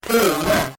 Uttal: [²p'u:ma]